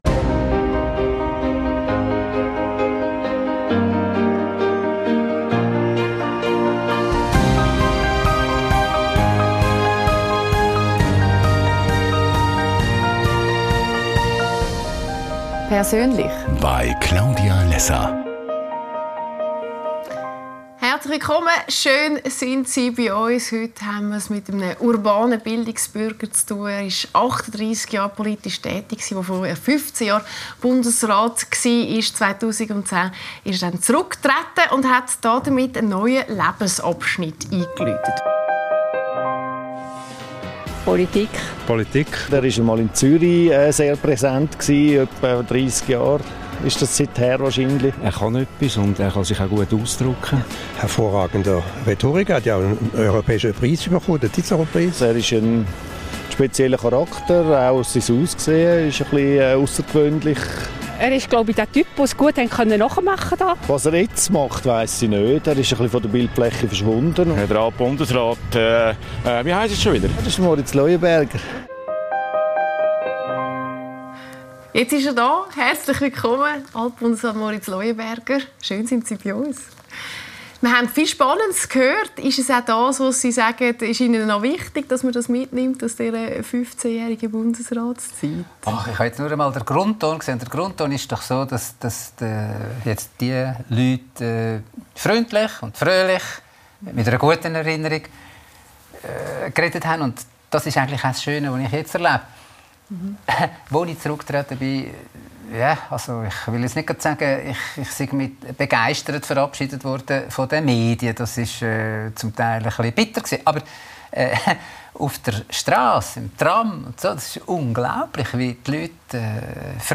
Lässer Classics – mit Moritz Leuenberger ~ LÄSSER ⎥ Die Talkshow Podcast